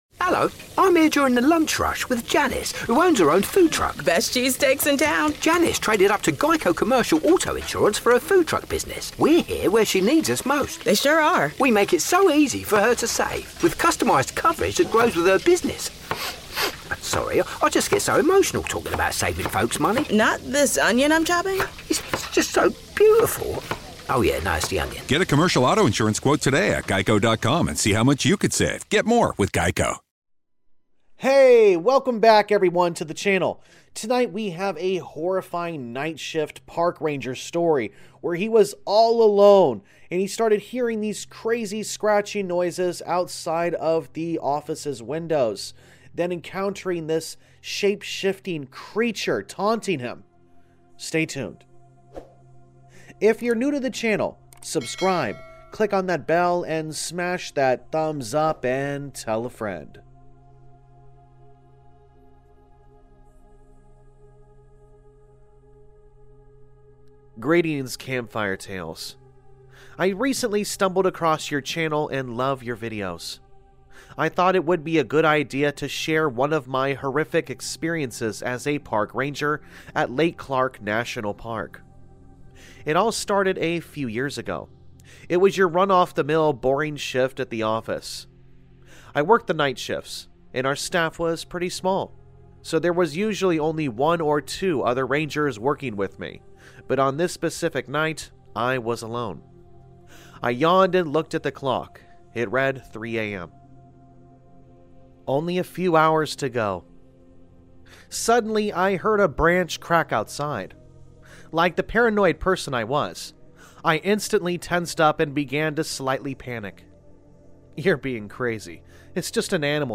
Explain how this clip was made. All Stories are read with full permission from the authors: Story Credit - sensitive-bull